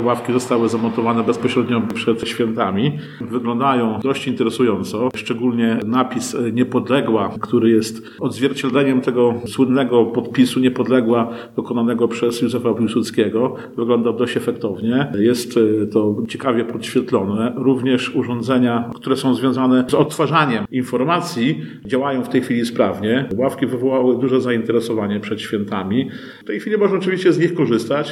– Ławki wzbudziły zainteresowanie – mówi Dariusz Latarowski, burmistrz Grajewa.